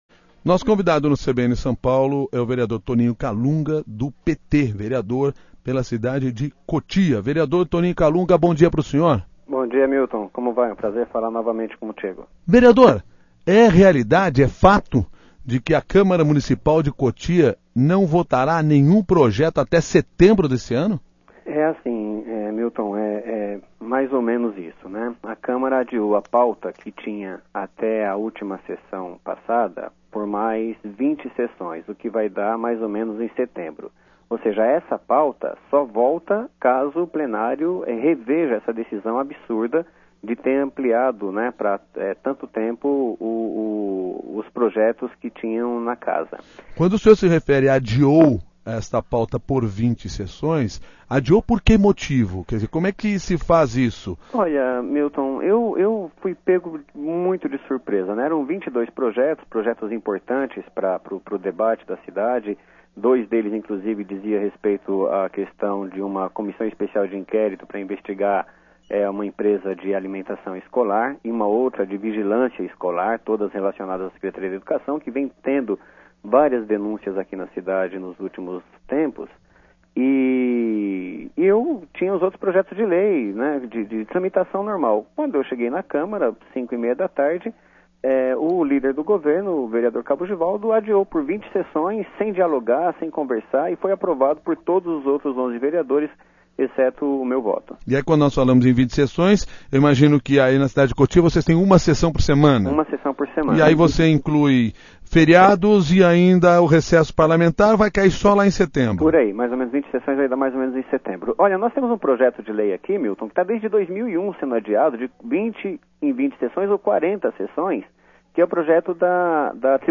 Ouça a entrevista com o vereador Antonio Kalunga (PT) ao CBN São Paulo